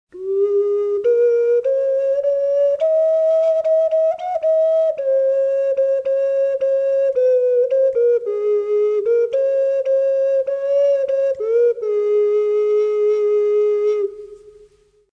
Sählypallo-okariina
sormireikää mahdollistaa liikkumisen oktaavin alueella.
hlypallo-okariinan skaala muodostuu seuraavaksi:
perussävel (kaikki sormireiät suljettuina) jossain a:n huitteilla,
duuriterssi (yksi reikä auki) ,
kvintti (kaksi reikää auki ),
oktaavi (kaikki reiät auki).